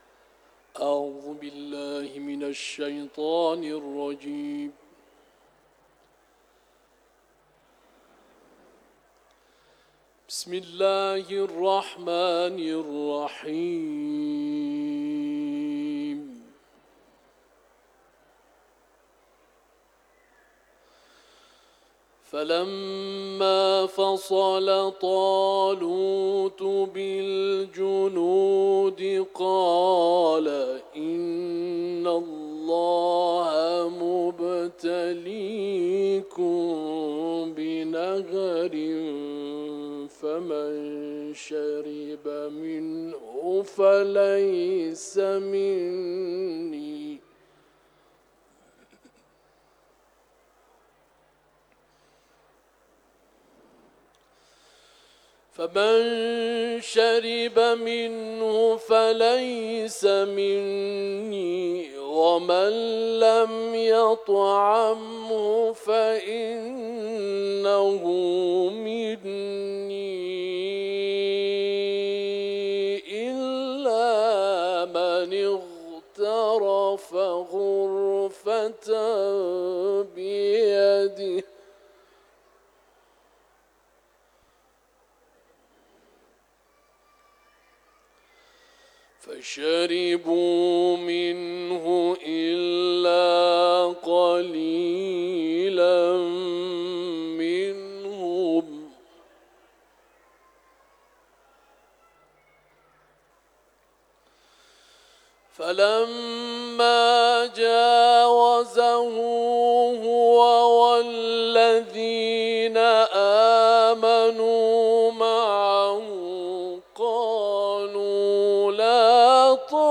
سوره بقره ، حرم مطهر رضوی ، تلاوت قرآن کریم